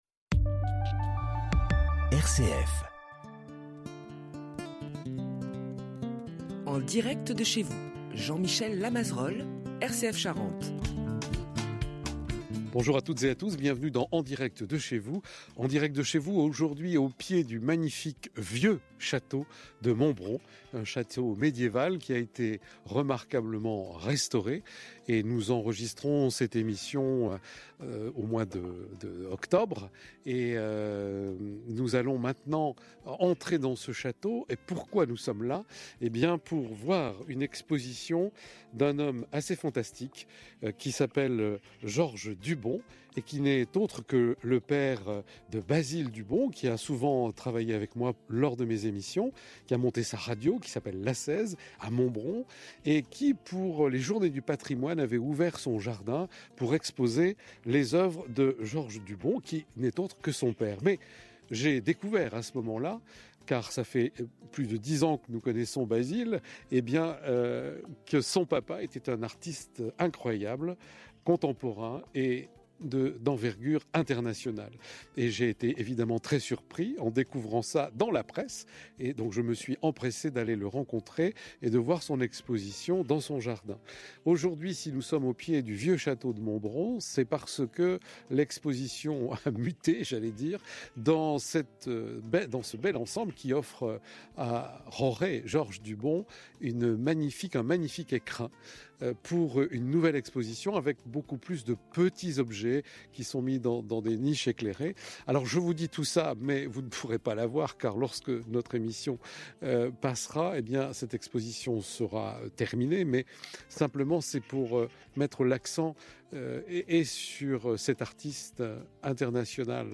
interview-rcf-novembre-2024.mp3